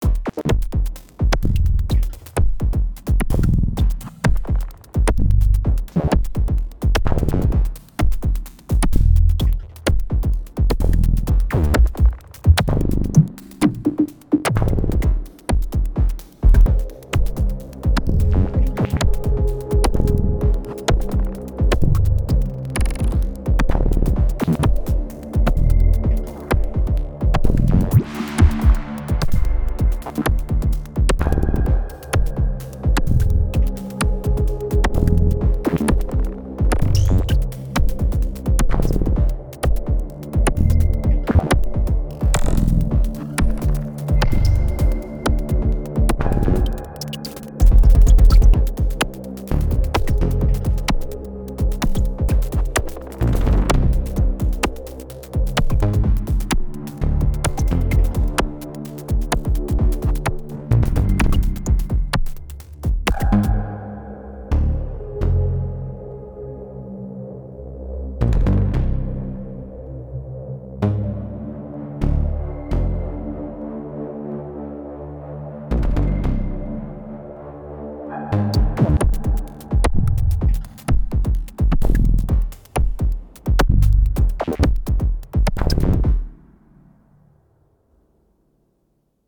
I ran some of the voices directly in the verb so it’s wet only and then the verb in the FX track with some p-locking.
I used the double notch filter on th FX track for this phasey-sound, as well as short delay times.